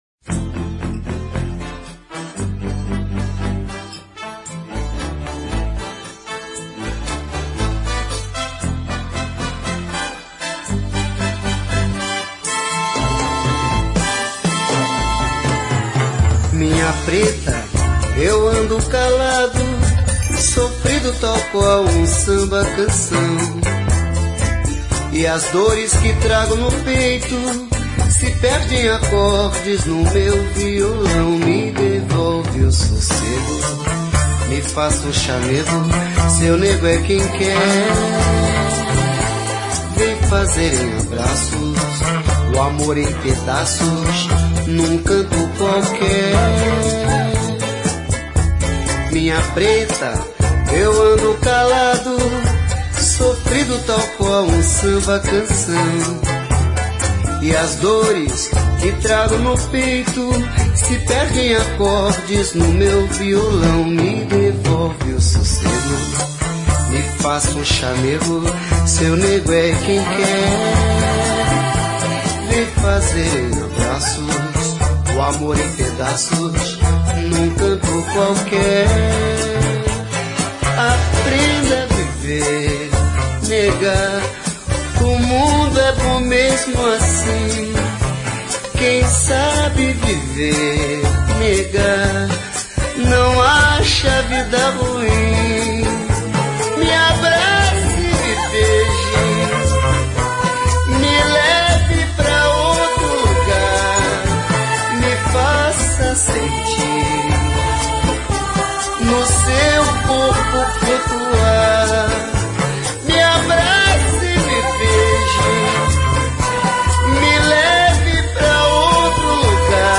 Sambas